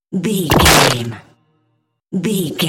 Dramatic hit wood laser
Sound Effects
Atonal
heavy
intense
dark
aggressive